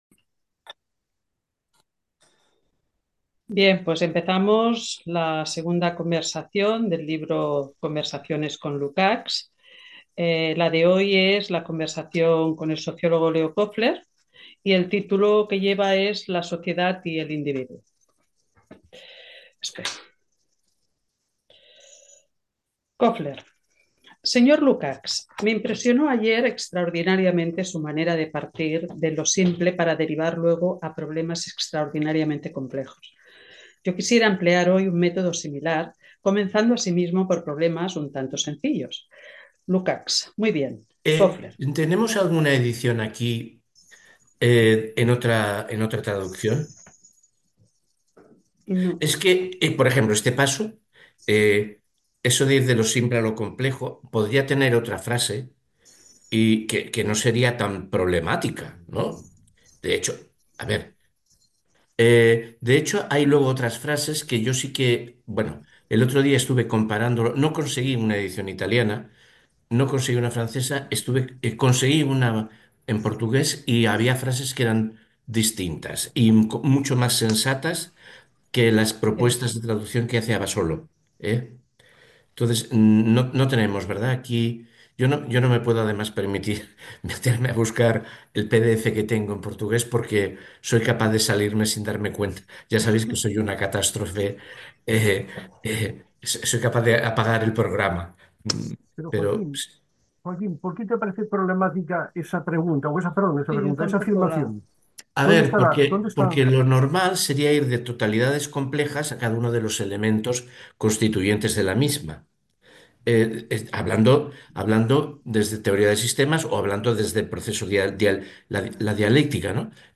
La forma de proceder es leer anticipadamente unas 20 o 25 páginas de texto, que posteriormente son releídas y comentadas en una puesta en común, que dirige un monitor.
En cualquier caso, la grabación del seminario será publicada posteriormente en la web de Espaimarx.